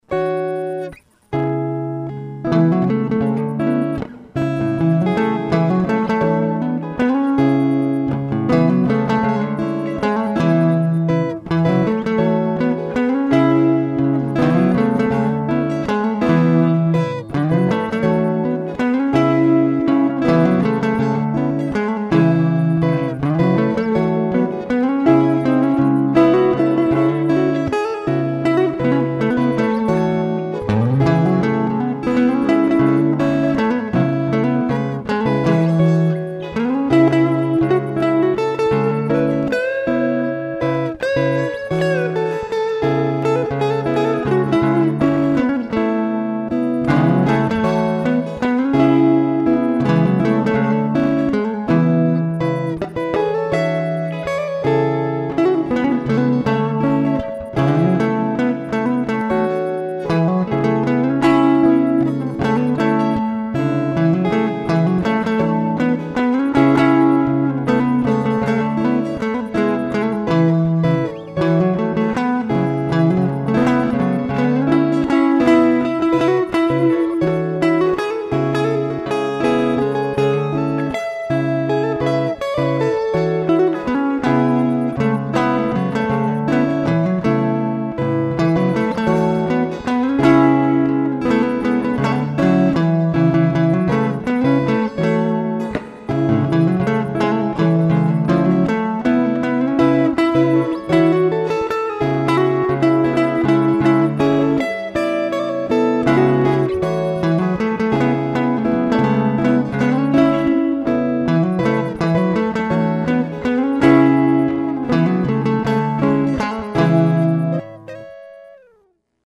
It only has the guitar tracks right now, but he will be adding other music tracks as well as lyrics soon.